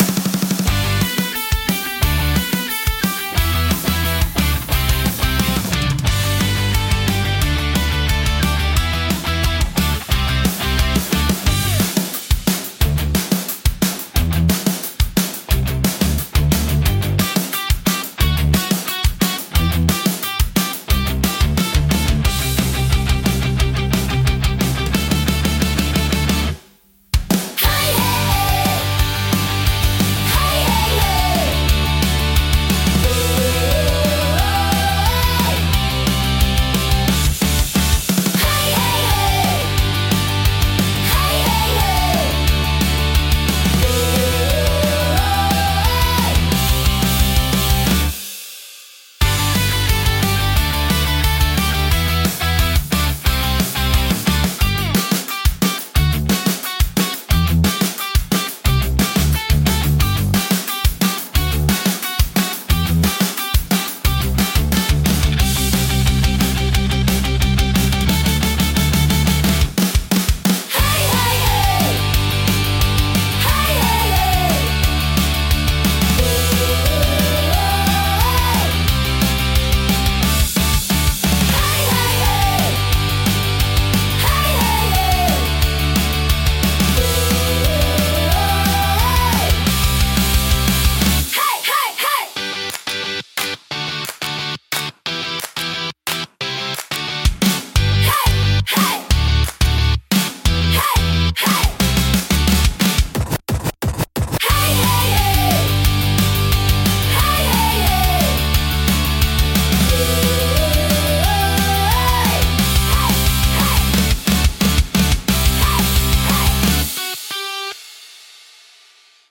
生々しさと疾走感を併せ持つ力強いジャンルです。